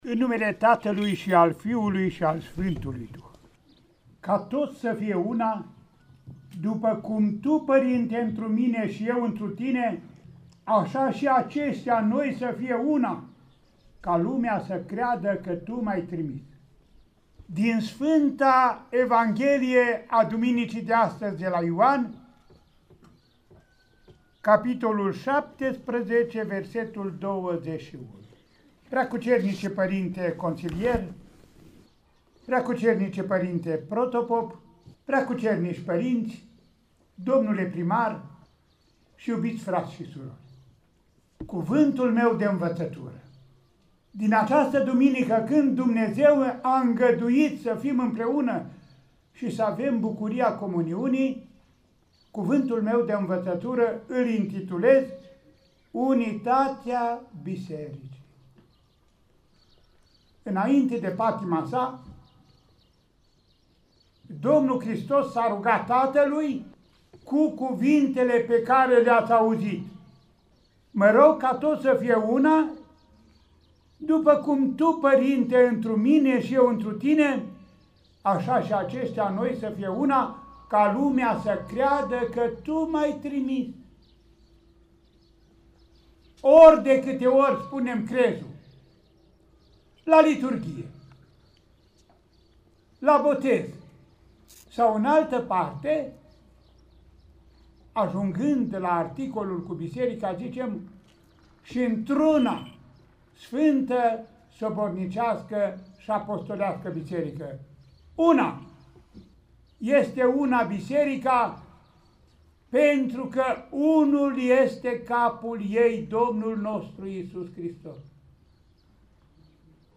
Mitropolitul Andrei: „Unitatea Bisericii” | Predică la Duminica a 7-a după Paști